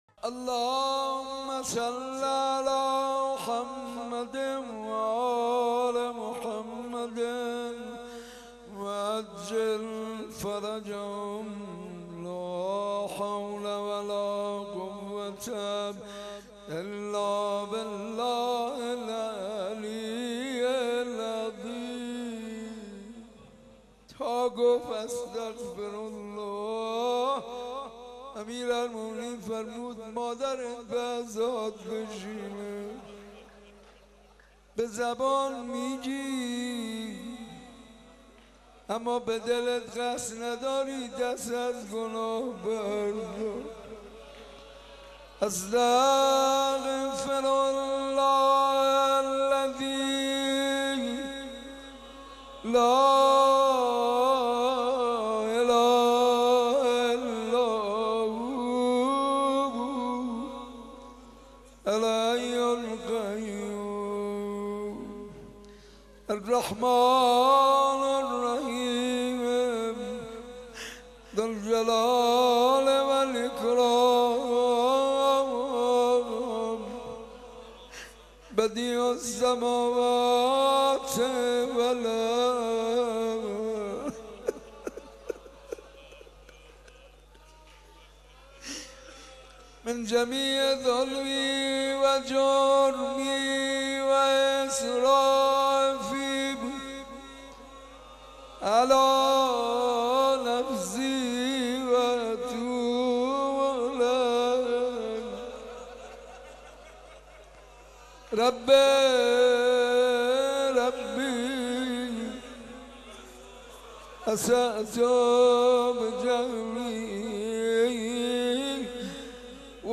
مناجات با خدا - حاج منصور ارضی